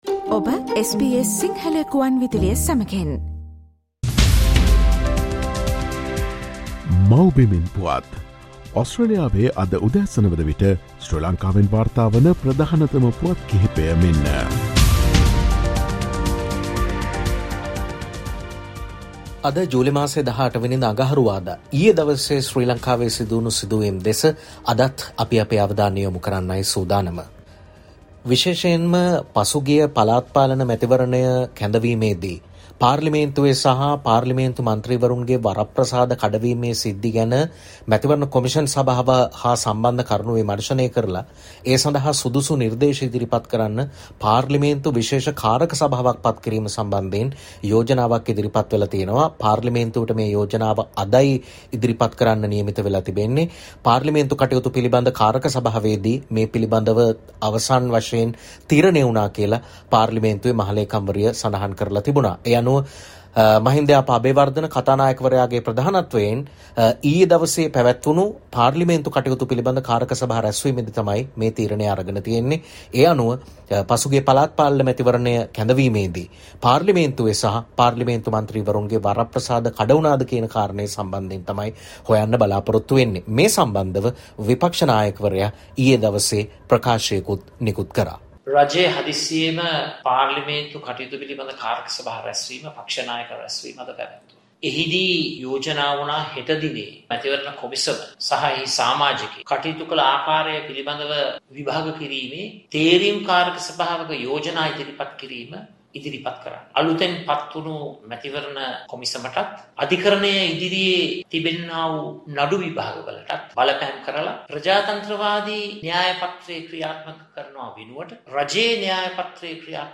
SL News report July 18: Special committee from Min. Keheliya, to look into the allegations made on medicines